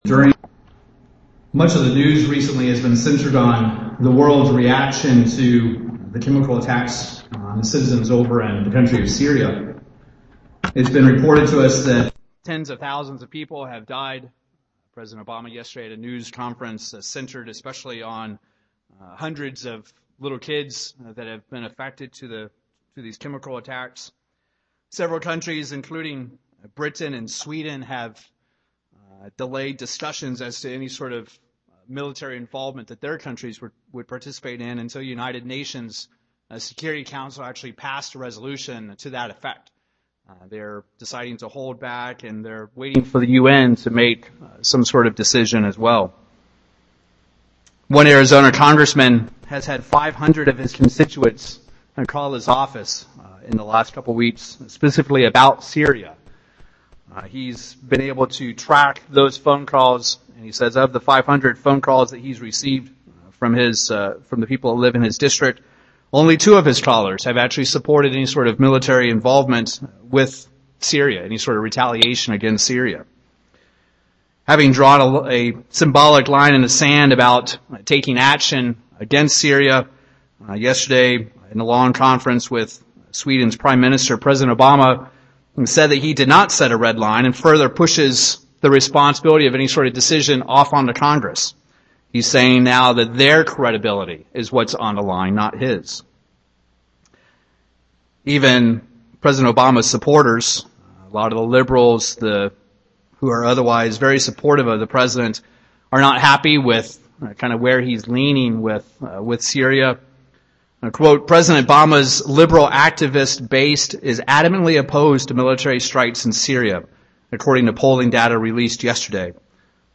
In this sermon, we will review some key events of the Feast of Trumpets. And in doing so, answer the question of whether this could be the beginning of the end.